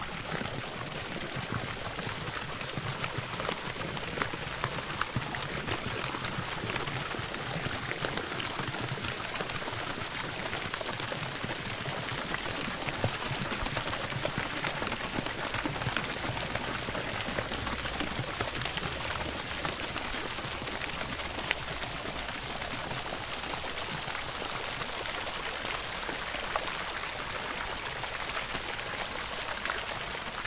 Tag: 场记录 喷泉 室外 博洛尼亚 声景